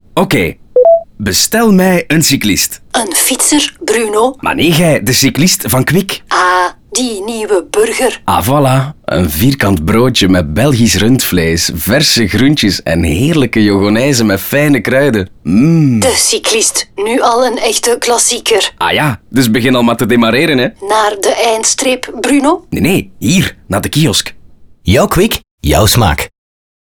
Happiness bedacht het concept van de nieuwe burger, een TV-billboard, een nieuwe audiospot in de restaurants, (D)OOH in 2, 8, 10 en 20 m2, POS-materiaal én een socialmediacampagne.